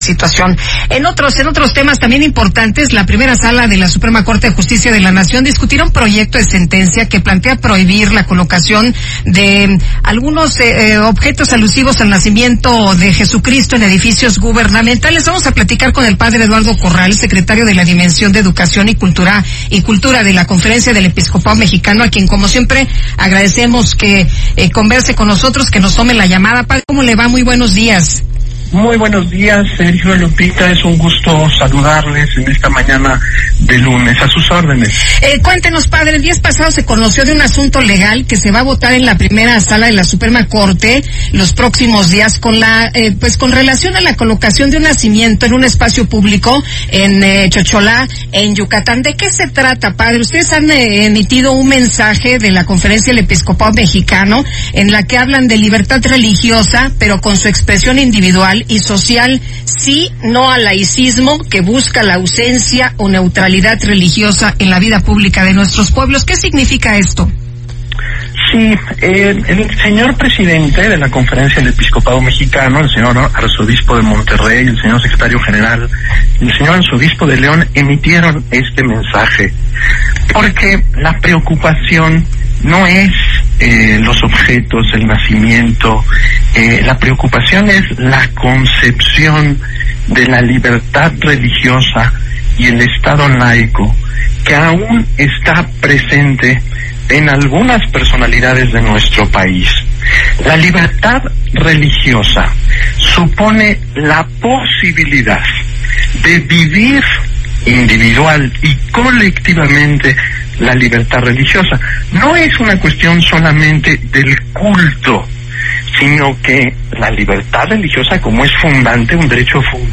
Entrevista en heraldo radio